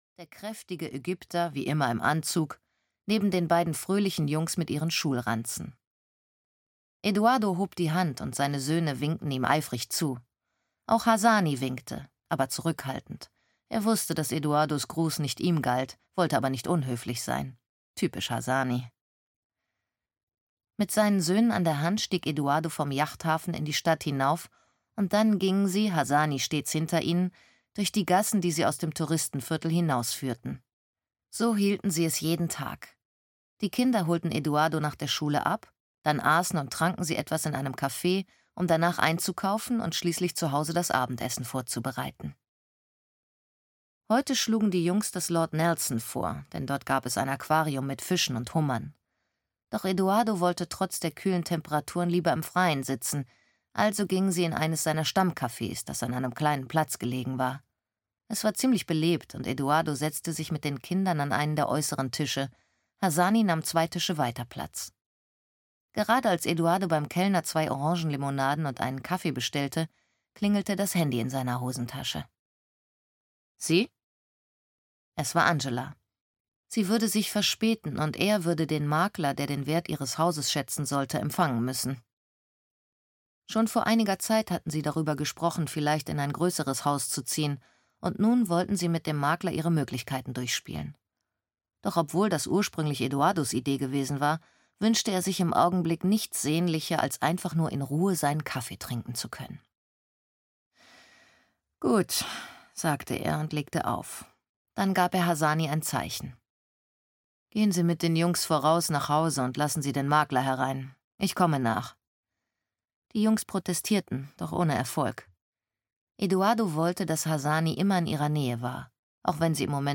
Der zweite Sohn (Die Sophie-Brinkmann-Trilogie 2) - Alexander Söderberg - Hörbuch